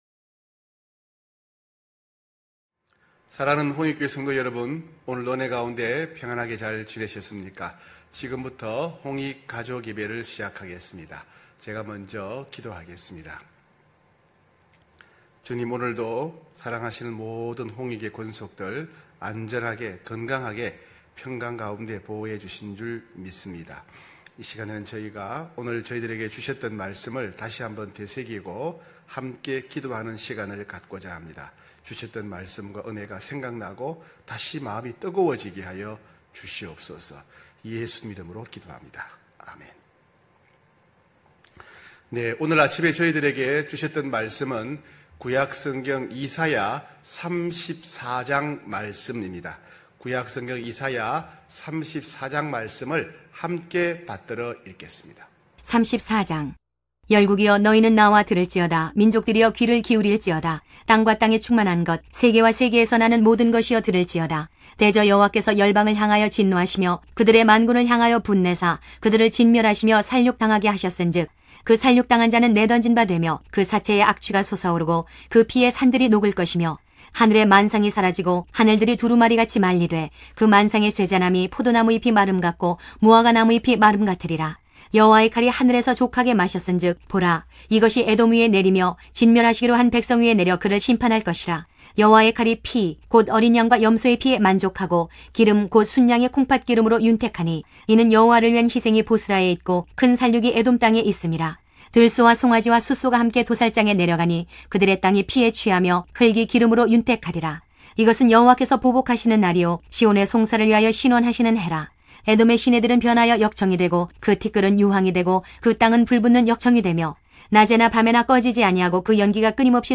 9시홍익가족예배(8월25일).mp3